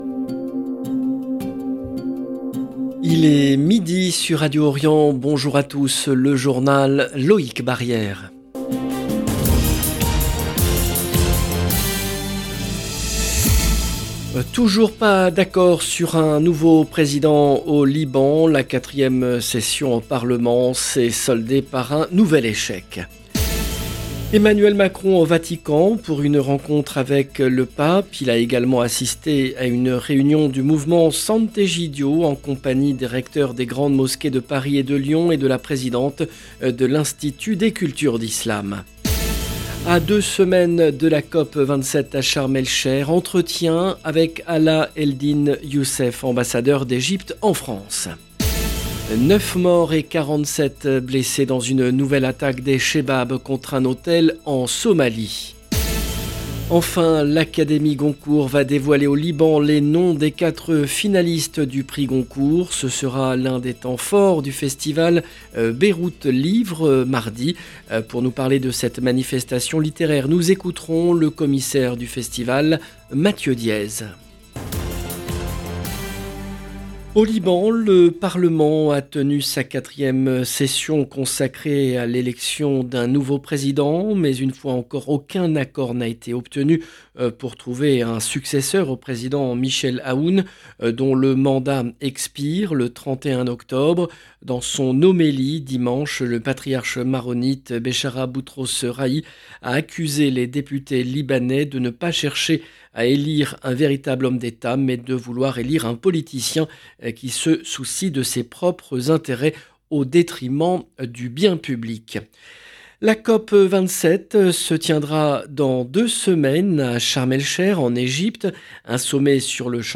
Dans deux semaines de la COP 27 à Charm El Cheikh : quels sont les enjeux de ce sommet sur le climat ? Entretien avec Alaa Eldin Youssef, ambassadeur d’Egypte en France. 9 morts et 47 blessés dans une nouvelle attaque des Shebab contre un hôtel en Somalie.